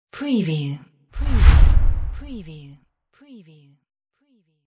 Transition whoosh 18
Stereo sound effect - Wav.16 bit/44.1 KHz and Mp3 128 Kbps
previewTLFE_DISTORTED_TR_WBHD18.mp3